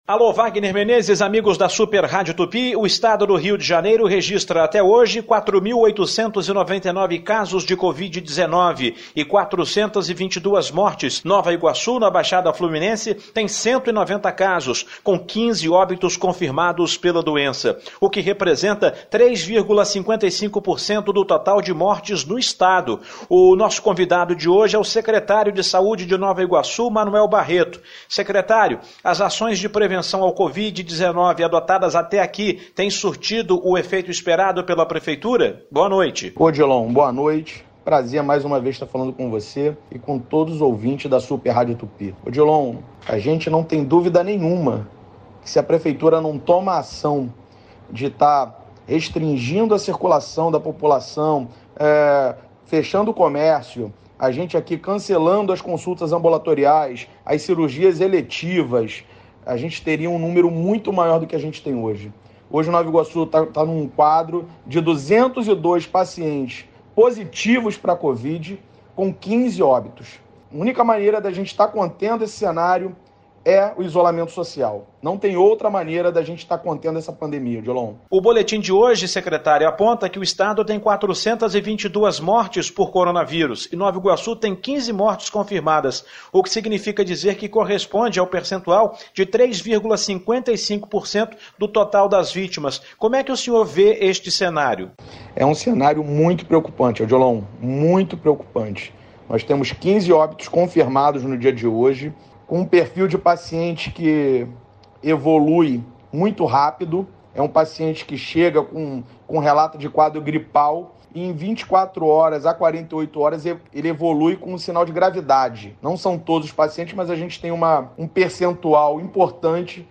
Secretário de saúde de Nova Iguaçu concede entrevista à Rádio Tupi